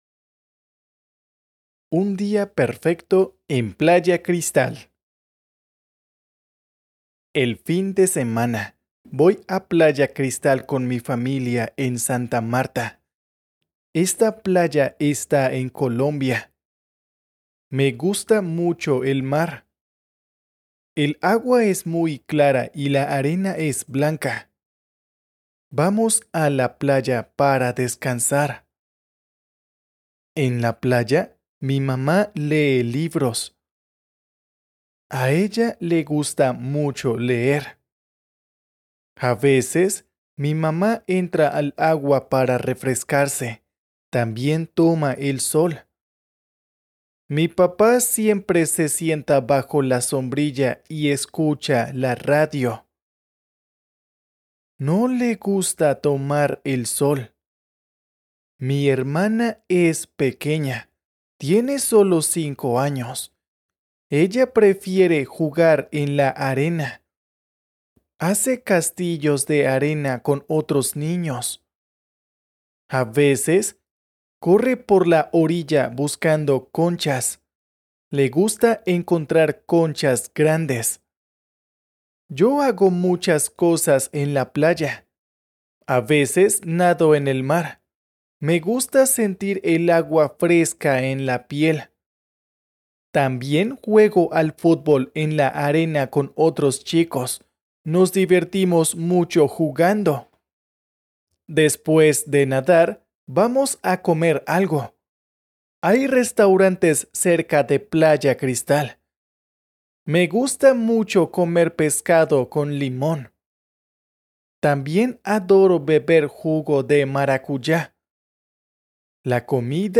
Spanish online reading and listening practice – level A1